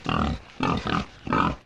flesh_idle_2.ogg